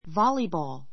vɑ́libɔːl ヴァ りボー る ｜ vɔ́libɔːl ヴォ りボー る